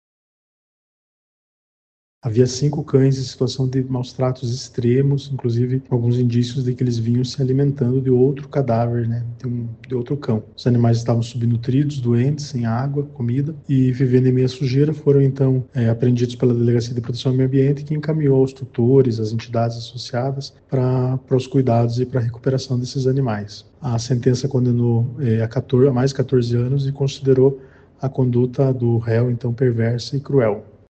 De acordo com o MPPR, o homem era funcionário de um pet shop de Curitiba e mantinha em uma chácara em Quatro Barras cinco cães em condições graves de subnutrição, como explica o promotor de Justiça, André Luiz de Araújo.